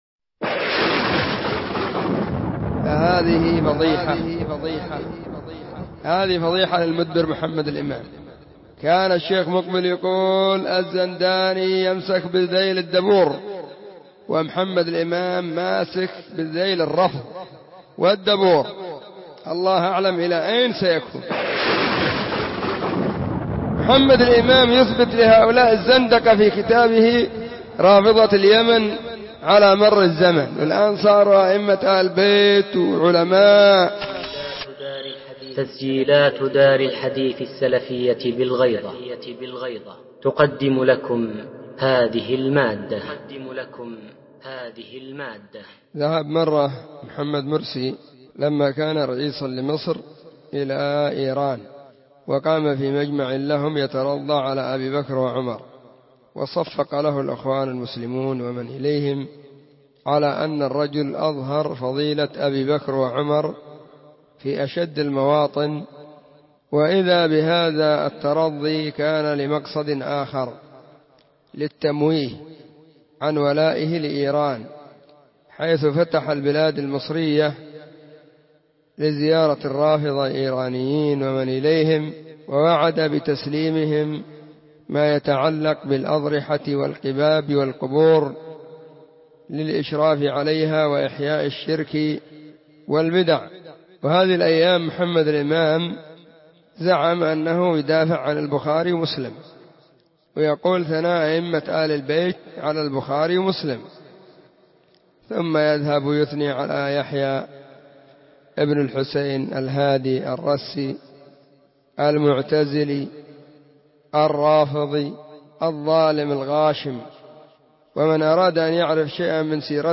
📢 مسجد الصحابة – بالغيضة – المهرة – اليمن حرسها الله.
الأثنين 20 صفر 1443 هــــ | الردود الصوتية | تعليق واحد